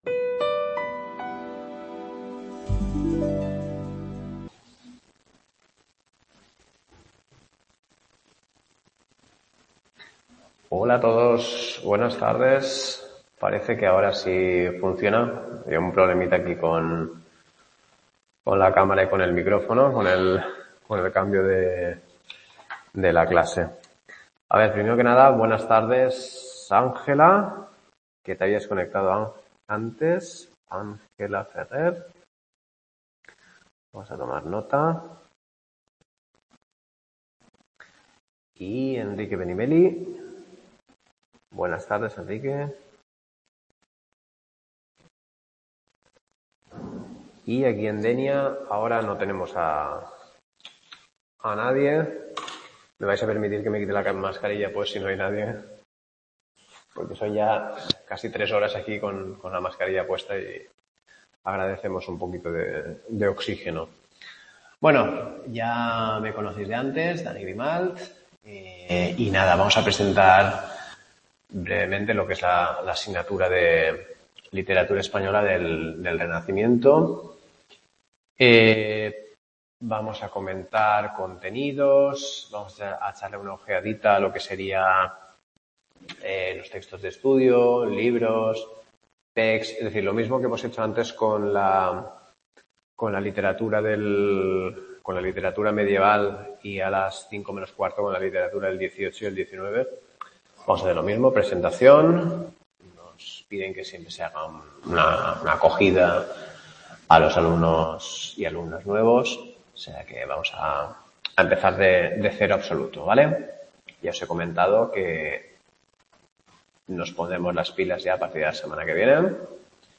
Clase 1 Literatura española del Renacimiento | Repositorio Digital